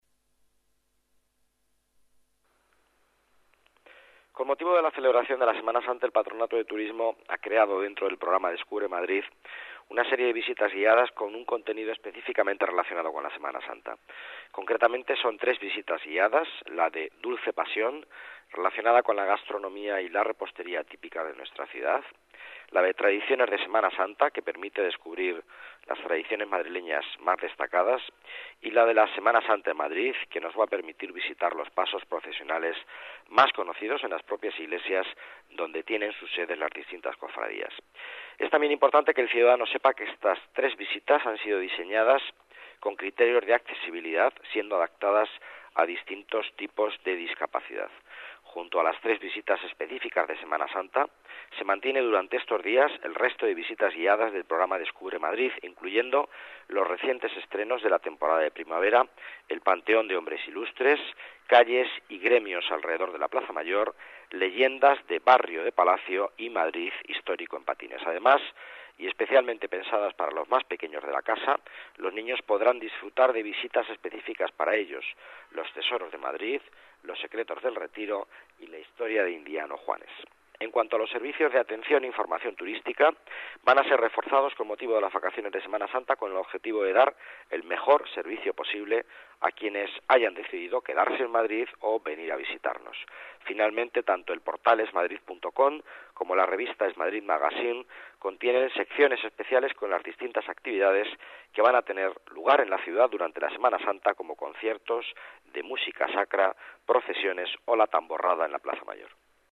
Nueva ventana:El delegado de Economía, Miguel Ángel Villanueva, explica el programa de turismo para Semana Santa